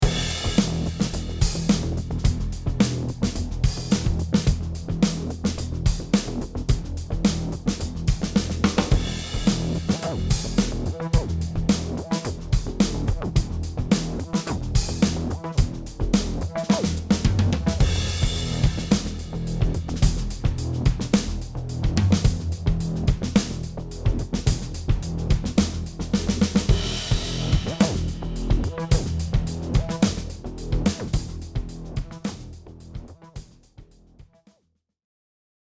下にあるオーディオサンプルは、全く同じ素材のマスターフェーダーに、HEarを使用したもの・していないものの2つのサンプルです。
上のサンプル（HEarなし）では左右に思いっきり広がっているシンセベースが、下のサンプル（HEarあり。スピーカー音場を再現）ではほどほど心地よいくらいの広がりに聞こえます。